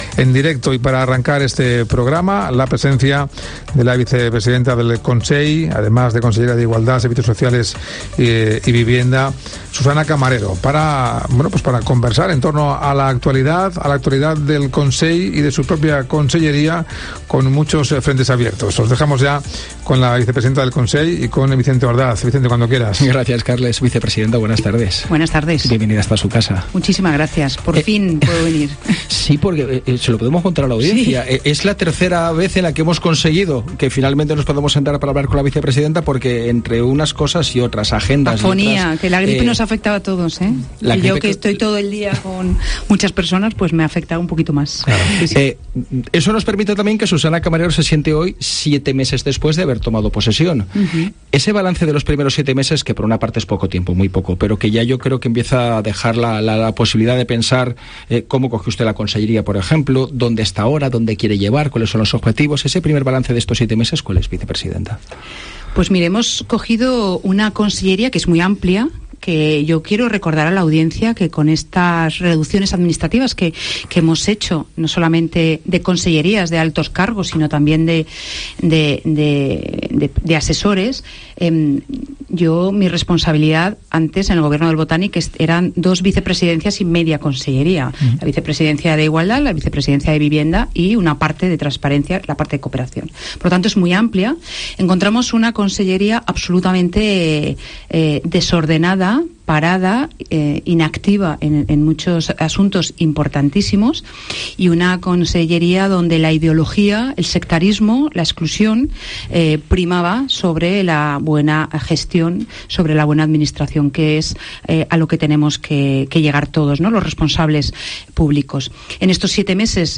La consellera de Igualdad y Asuntos Sociales, Susana Camarero, se ha mostrado muy disgustada en los micrófonos de COPE Valencia por la cantidad de...